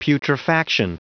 Prononciation du mot putrefaction en anglais (fichier audio)
Prononciation du mot : putrefaction